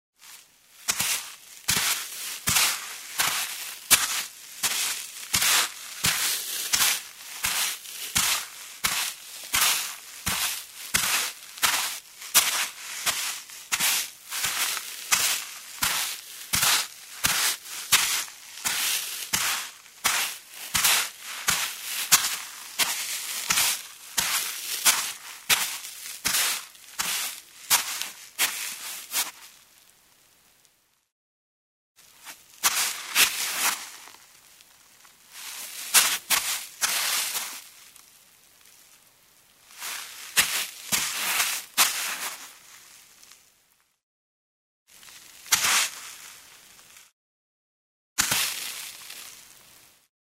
Шаги босиком по мокрому песку — второй вариант